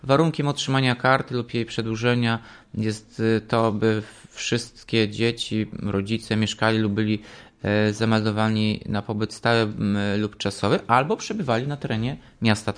Mówi Prezydent Ełku, Tomasz Andrukiewicz: